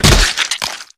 gore8.ogg